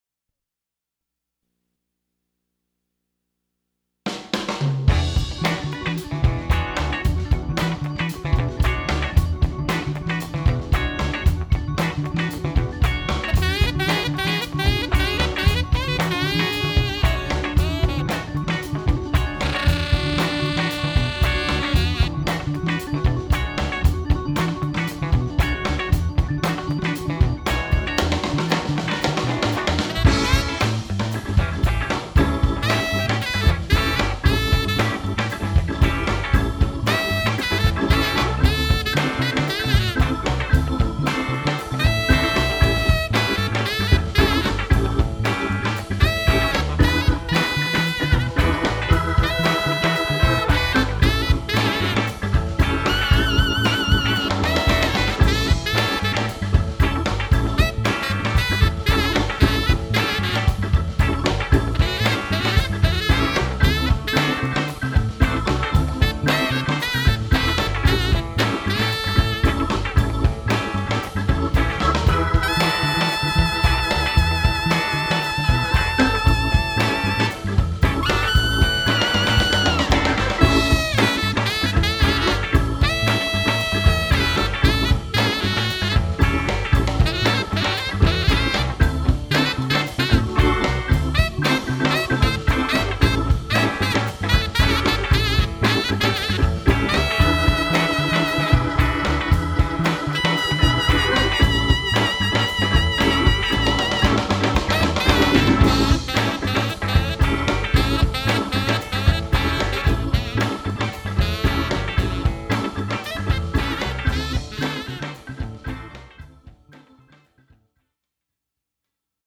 Recueil pour Saxophone